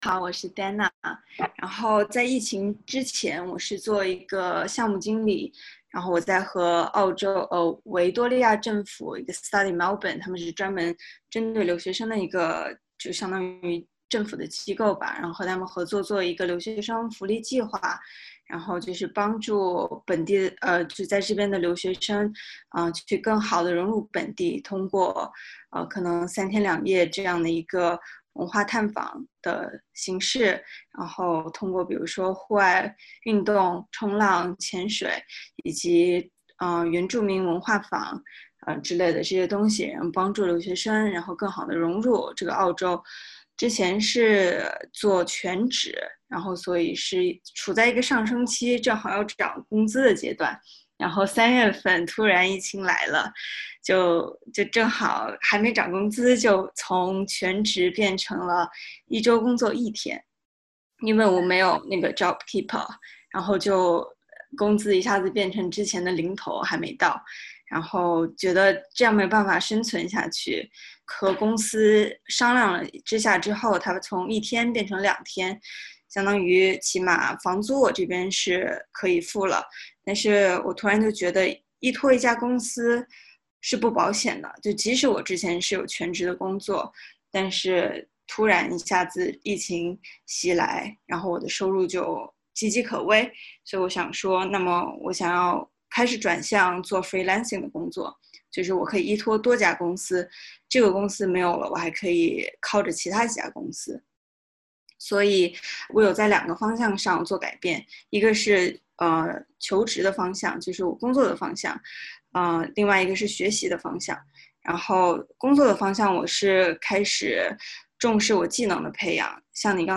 收入连从前的零头都不到，这让她开始彻底修改自己的职业规划……（点击封面图片，收听采访录音）